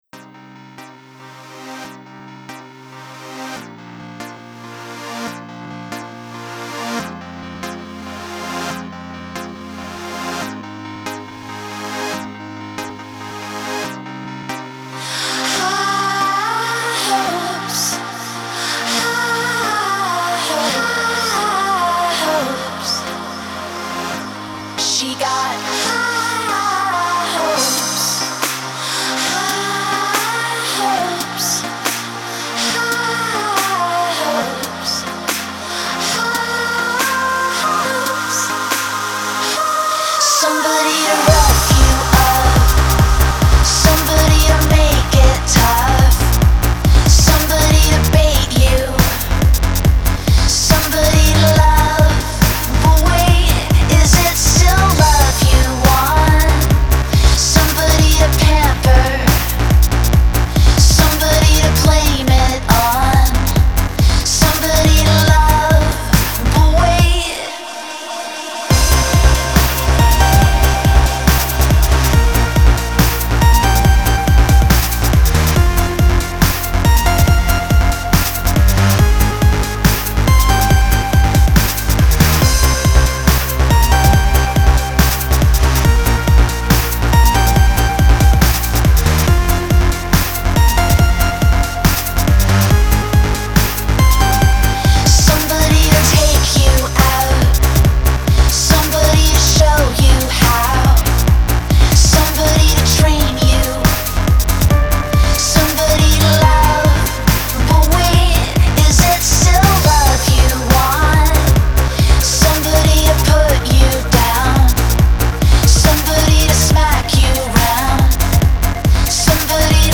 a dreamy dance track
airy, delicate vocals
reverbed synths